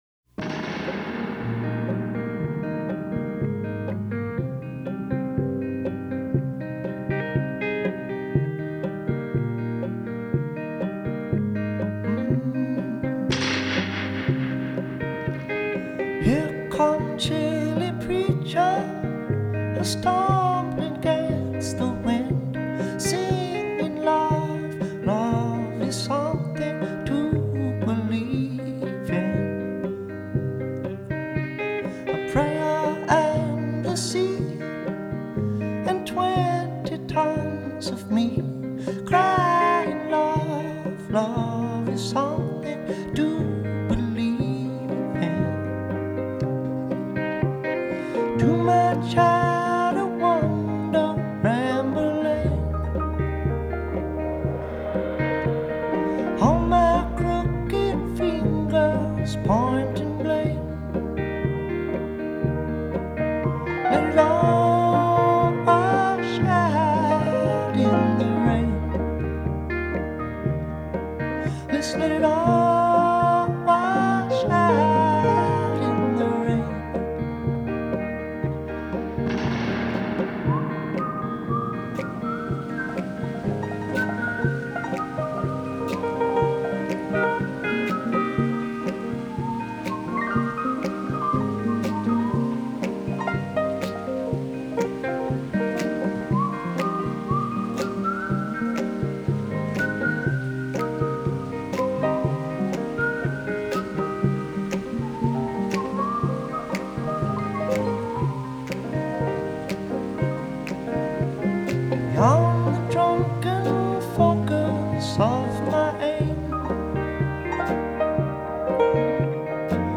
Today’s Indie: